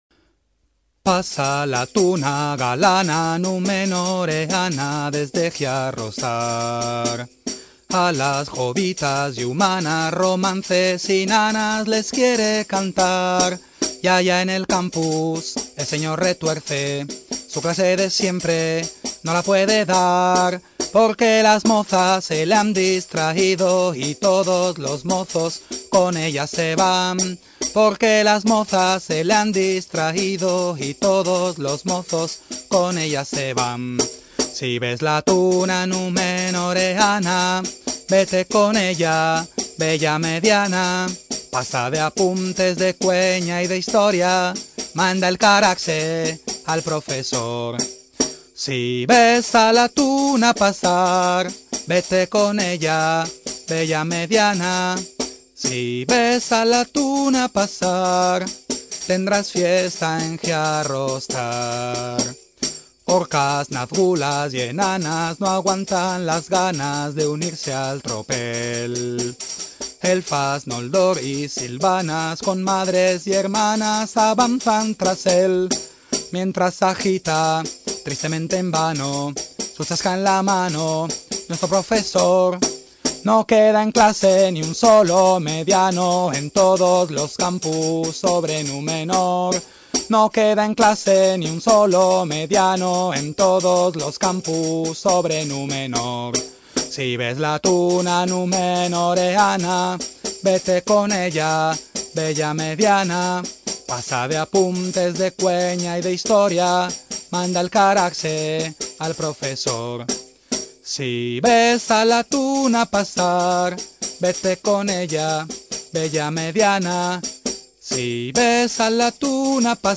VERSIÓN CANTADA